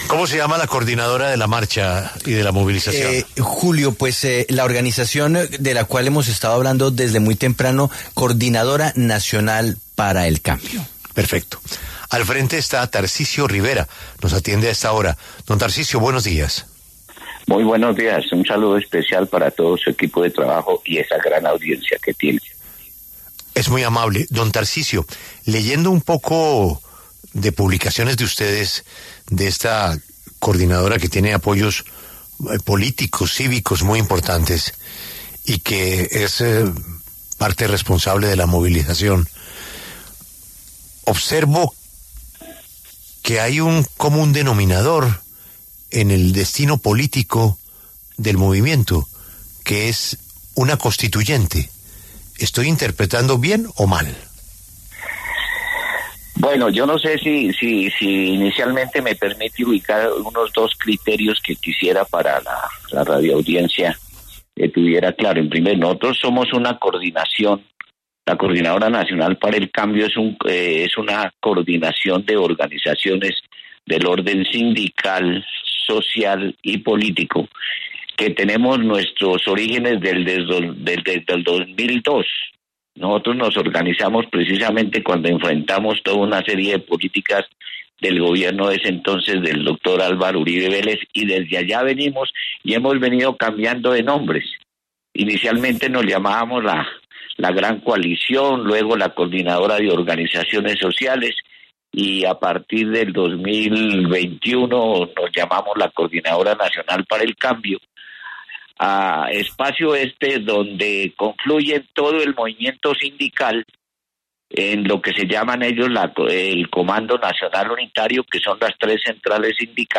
pasó por los micrófonos de La W, con Julio Sánchez Cristo, para hablar sobre esa organización, sus propósitos, sus directrices y su relación con el Gobierno del presidente Gustavo Petro.